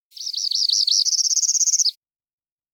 Song of the Northern Parula
370-d-northern-parula-type-b11.mp3